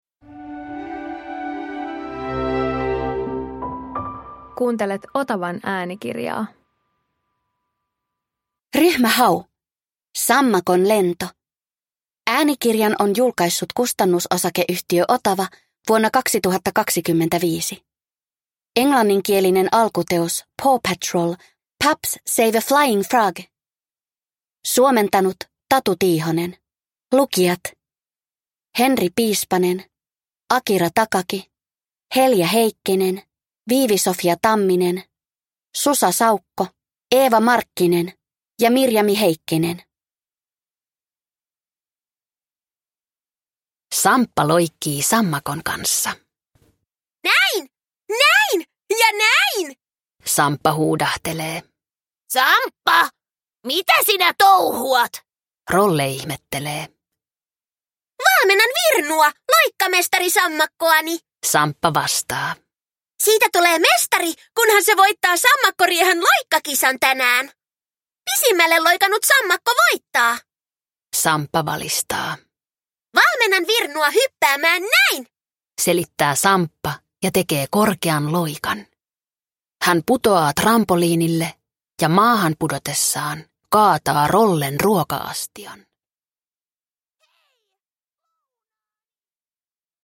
Ryhmä Hau - Sammakon lento – Ljudbok